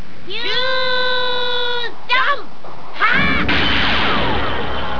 In questa pagina potete trovare i suoni in formato WAV / MP3 dei vari attacchi e delle tecniche speciali dei personaggi.